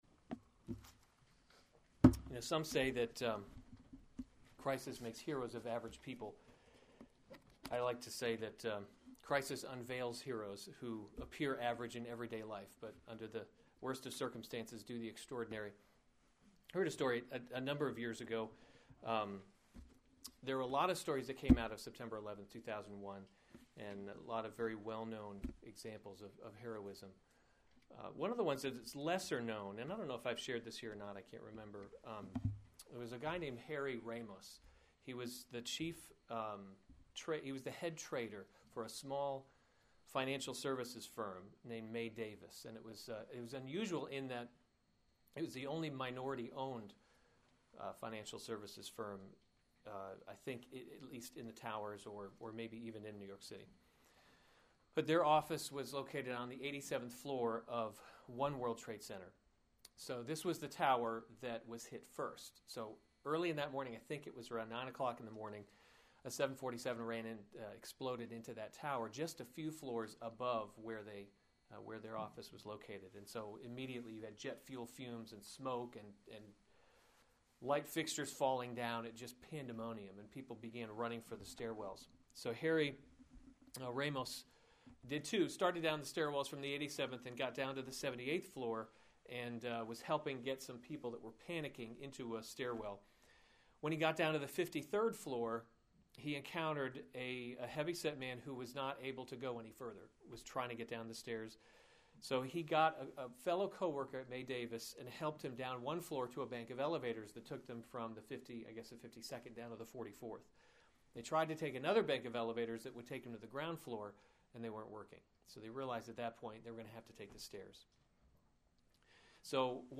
February 7, 2015 Romans – God’s Glory in Salvation series Weekly Sunday Service Save/Download this sermon Romans 12:1-2 Other sermons from Romans A Living Sacrifice 12:1 I appeal to you therefore, […]